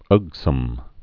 (ŭgsəm)